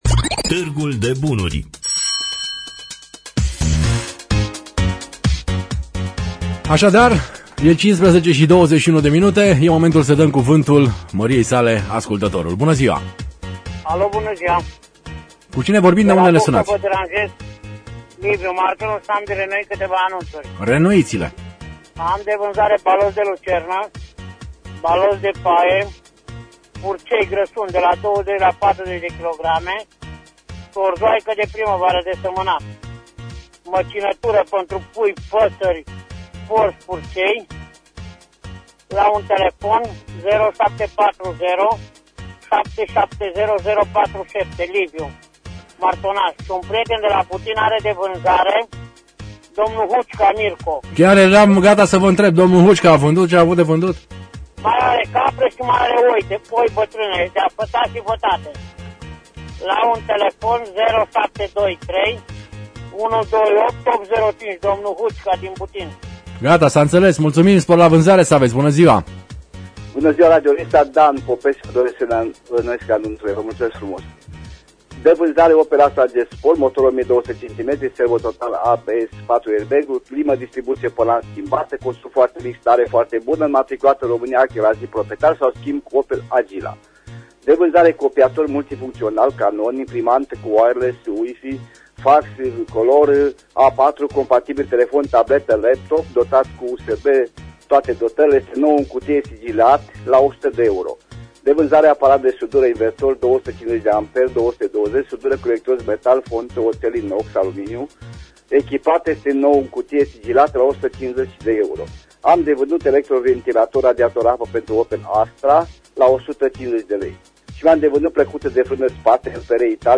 Înregistrarea emisiunii „Târgul de bunuri” de vineri, 11.03.2016, difuzată la Radio România Reşiţa.